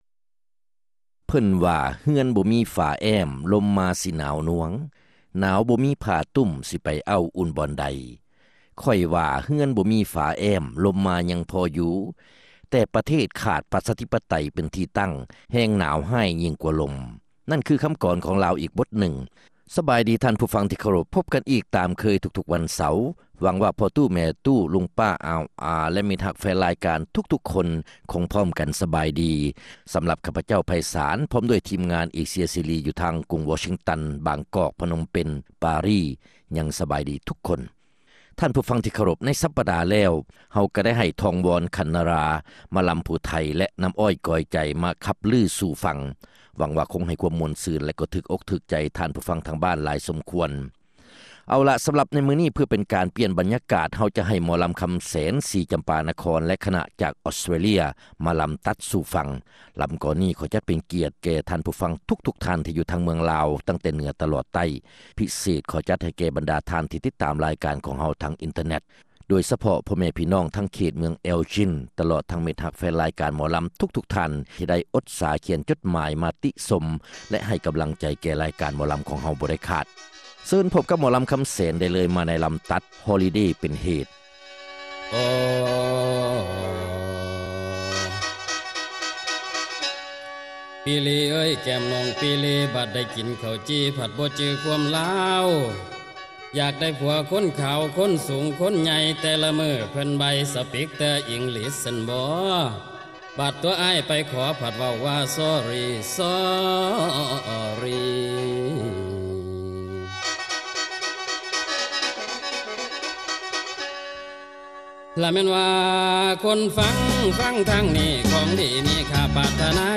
ຣາຍການໜໍລຳ ປະຈຳສັປະດາ ວັນທີ 10 ເດືອນ ກຸມພາ ປີ 2006